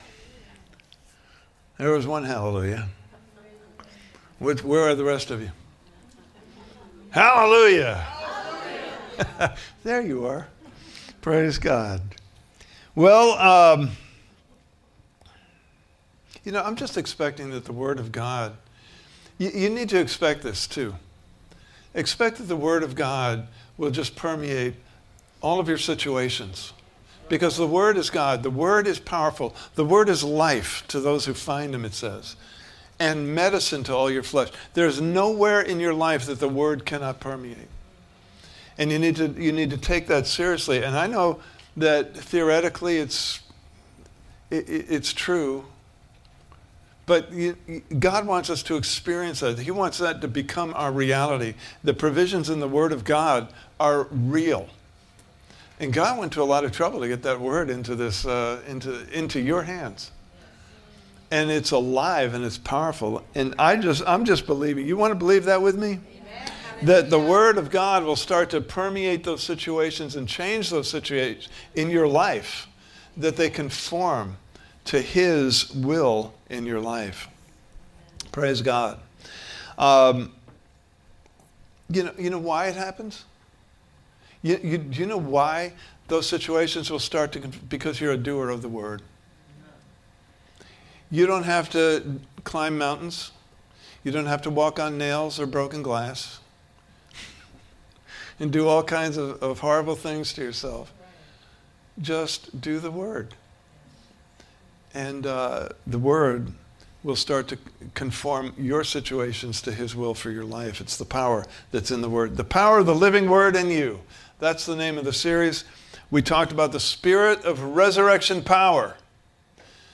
Service Type: Sunday Morning Service « Part 2: Death, the Defeated Foe!